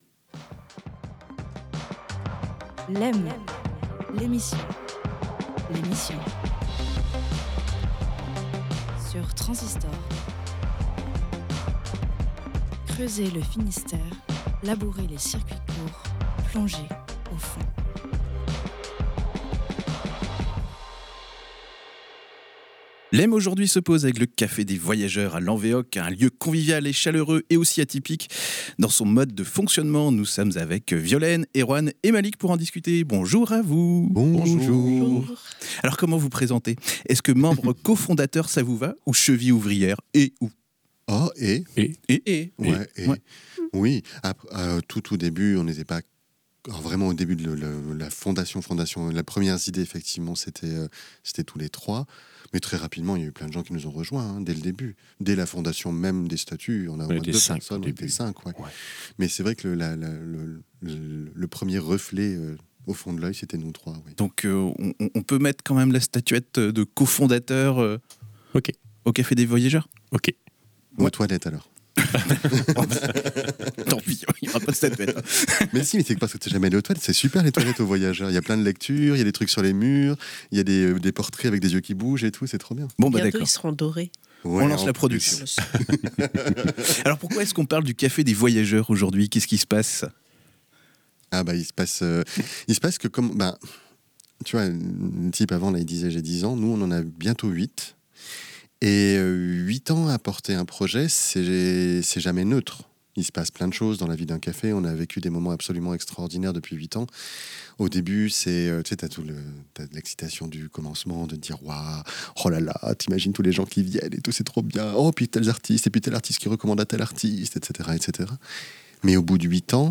Aujourd’hui, LEM pose ses valises au Café des Voyageurs à Lanvéoc, un lieu chaleureux et atypique qui, depuis huit ans, tisse du lien au cœur de la presqu'île de Crozon.